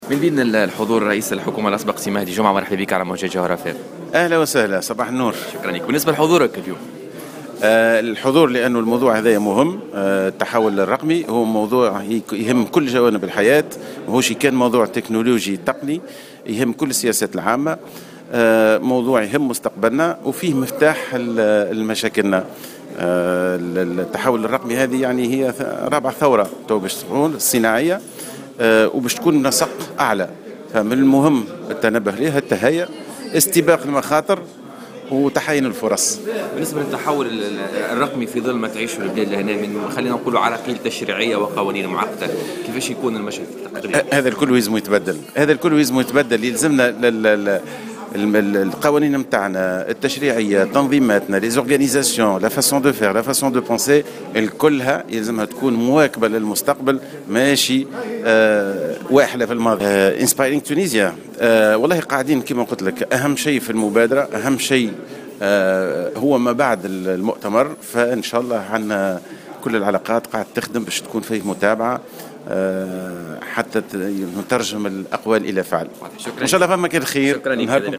أكد رئيس الحكومة الأسبق مهدي جمعة في تصريح للجوهرة "اف ام" على هامش افتتاح تظاهرة أيام المؤسسة اليوم الجمعة بسوسة أن موضوع هذه الدورة "التحول الرقمي" هو موضوع يهم كل جوانب الحياة ويهم السياسات العامة للبلاد وفيه مفتاح لكل مشاكلنا على حد قوله.